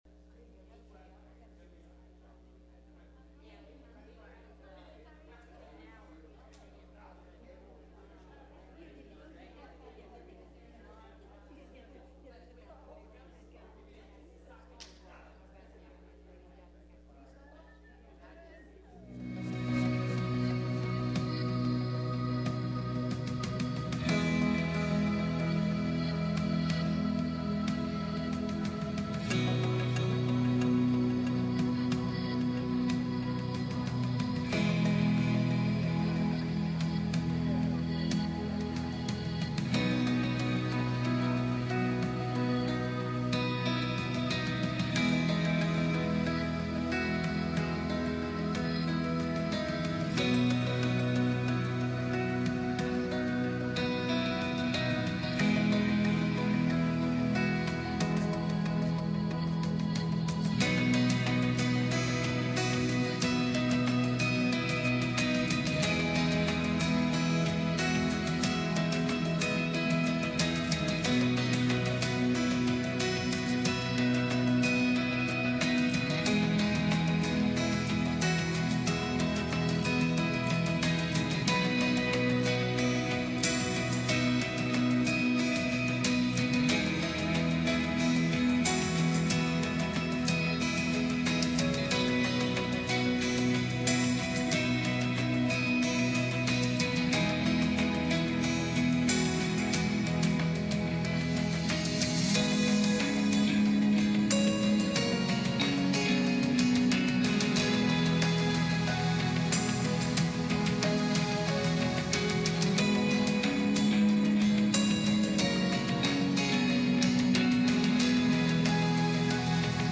1 Peter 3:18 Service Type: Sunday Evening « Surrender On Palm Sunday